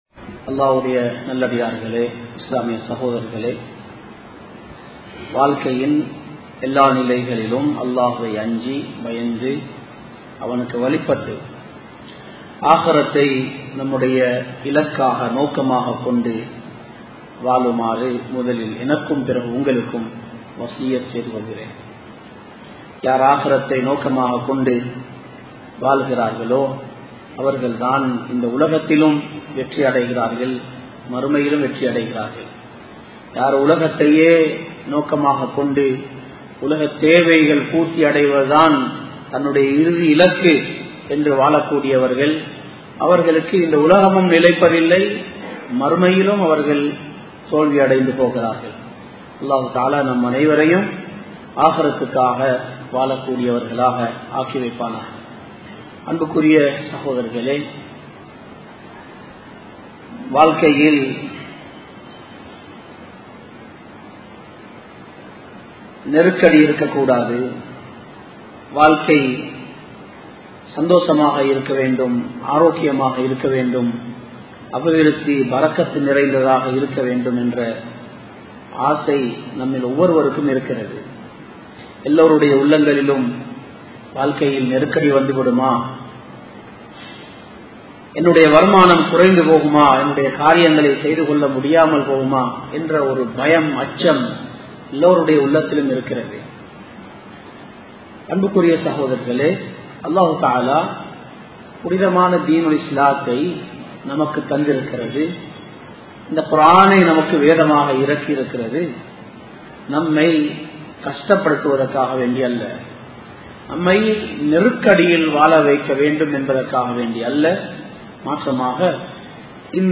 வாழ்க்கையில் பரக்கத் வேண்டுமா? | Audio Bayans | All Ceylon Muslim Youth Community | Addalaichenai
Samman Kottu Jumua Masjith (Red Masjith)